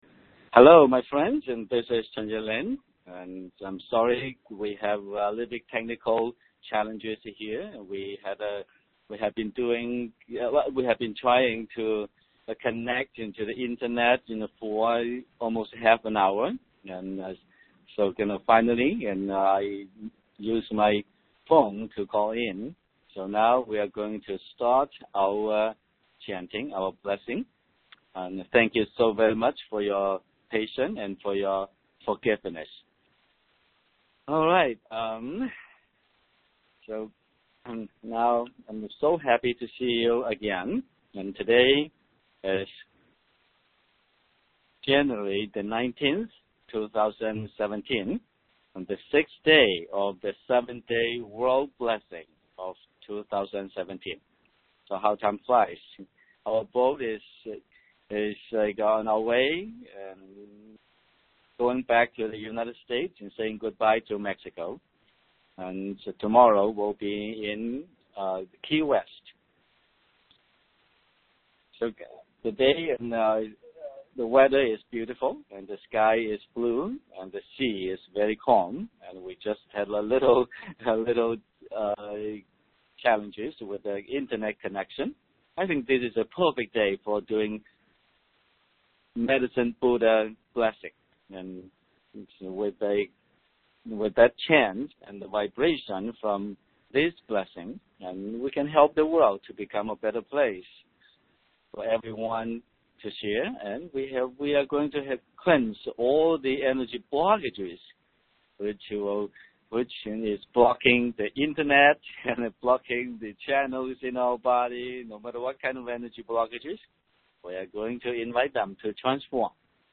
Replay of the 2017 Cruise Day 6 AM Meditation